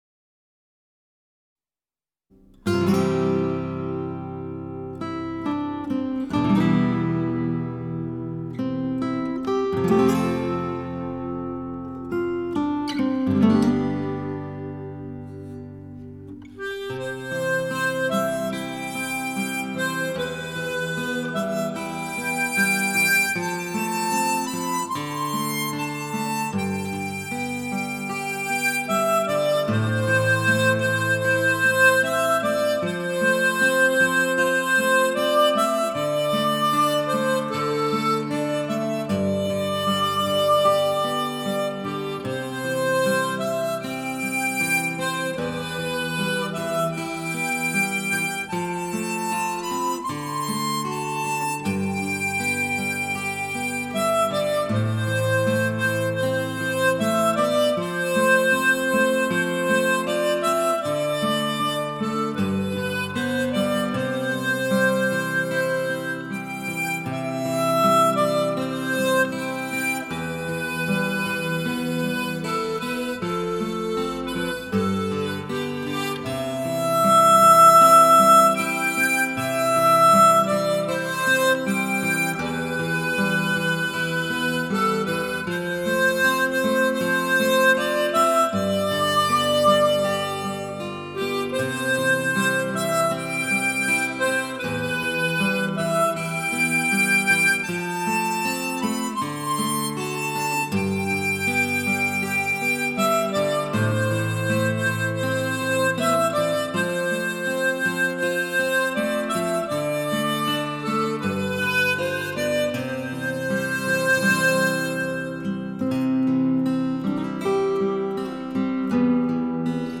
口琴演奏
无论是其独奏的曲目还是与结他、钢琴合奏的小品